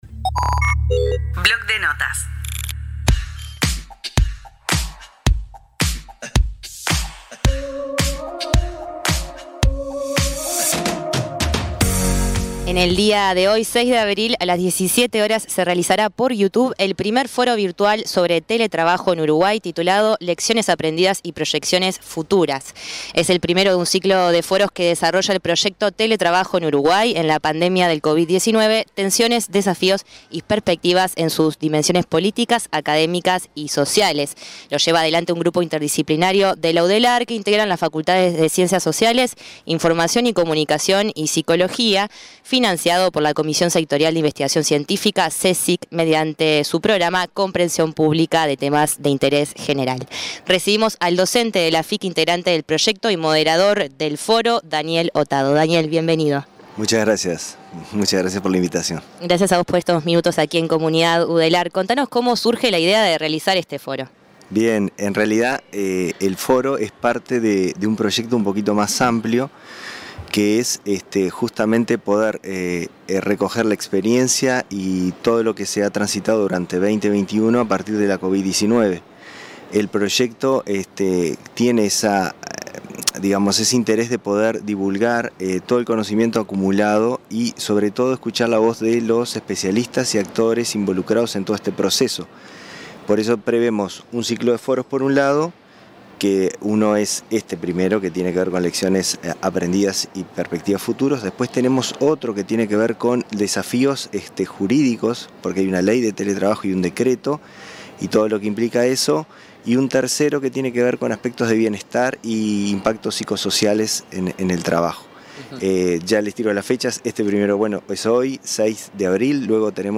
Comunidad Udelar, el periodístico de UNI Radio. Noticias, periodismo e investigación siempre desde una perspectiva universitaria.
Segunda transmisión especial de Comunidad Udelar desde Usina Modelo, en el predio del ex Mercado Modelo.